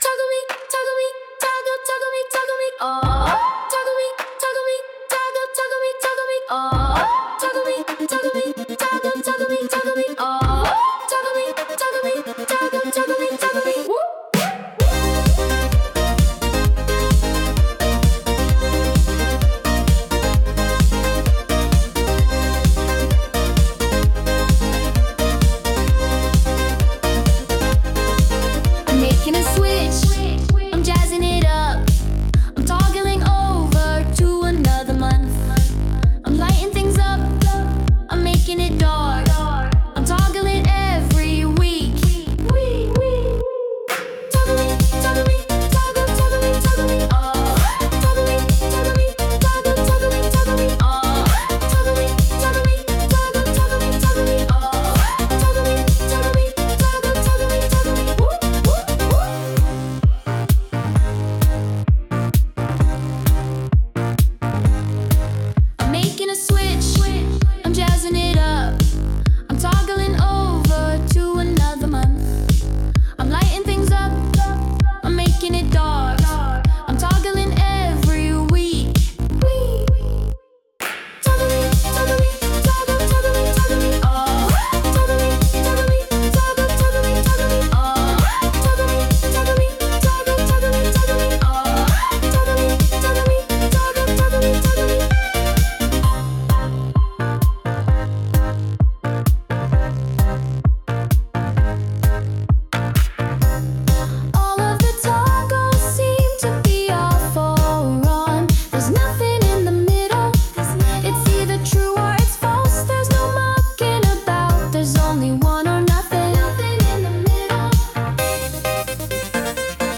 Sung by Suno
Toggle_Me_(Remix)_mp3.mp3